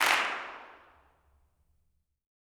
CLAPS 13.wav